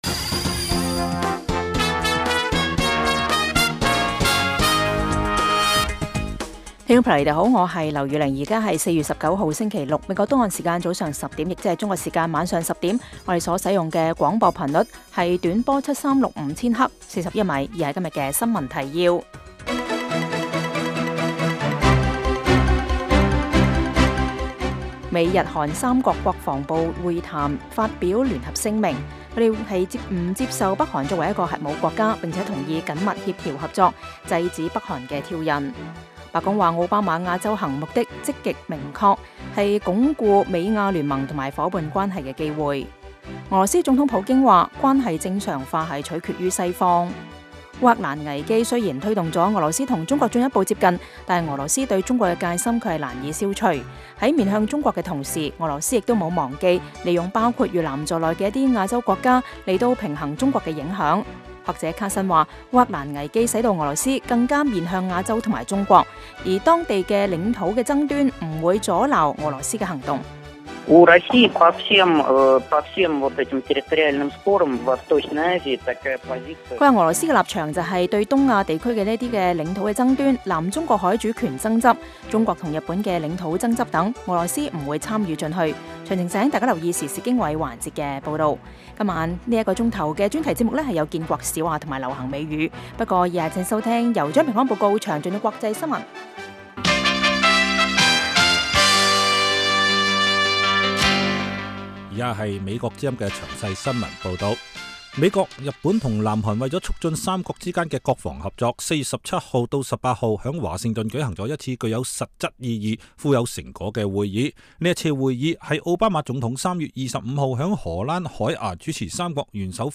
每晚 10點至11點 (1300-1400 UTC)粵語廣播，內容包括簡要新聞、記者報導和簡短專題。